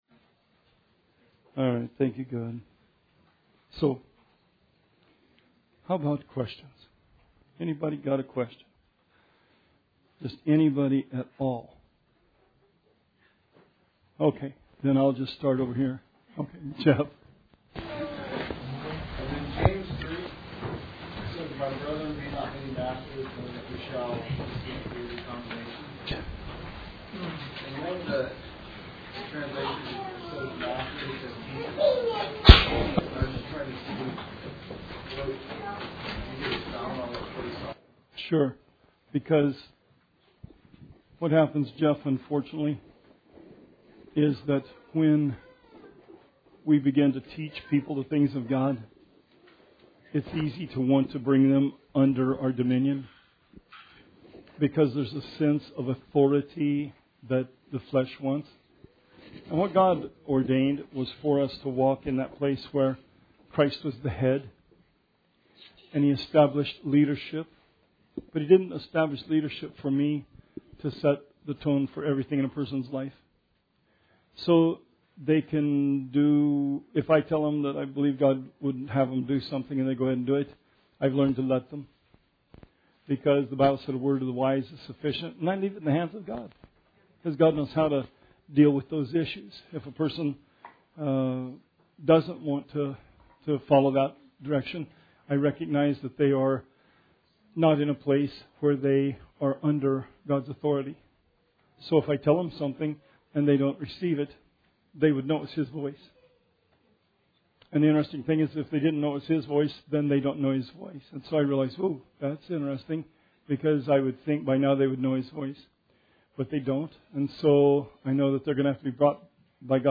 Bible Study 9/7/16